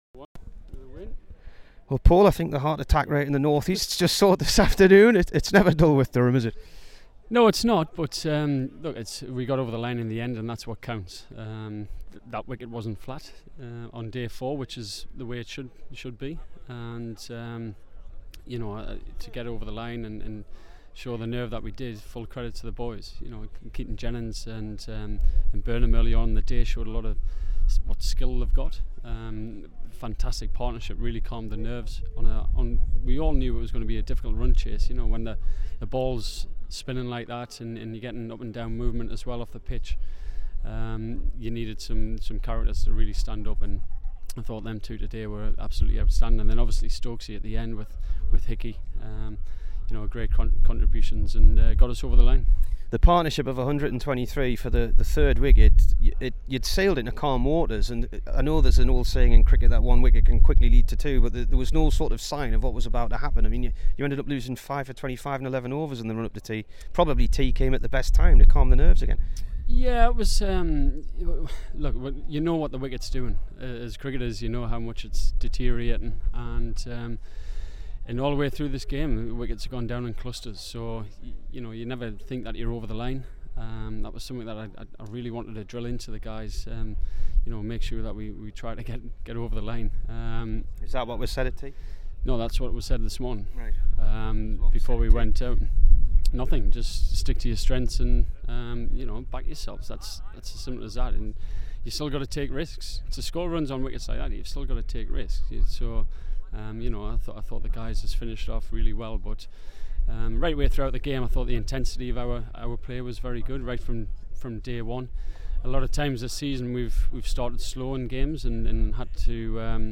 Here is the Durham captain after the championship win at Southport.